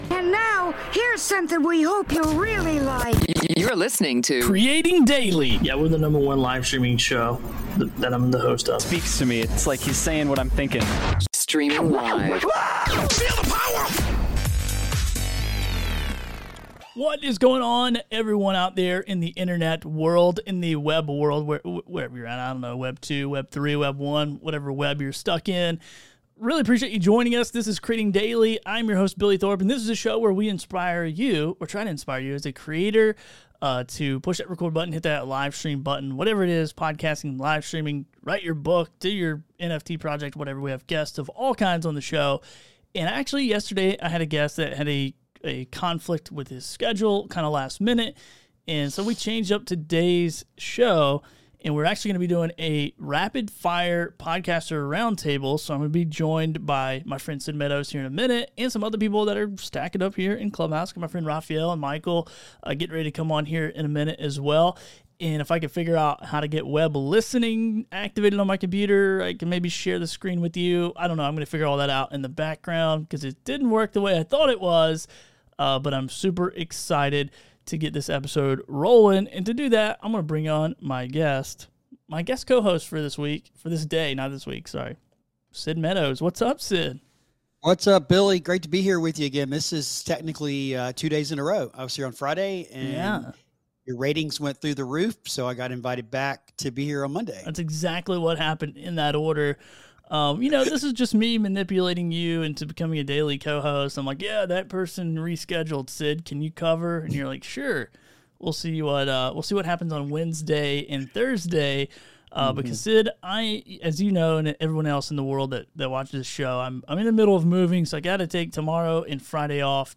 Come join the conversation as we host a rapid fire roundtable discussing various topics of podcasting and content creation.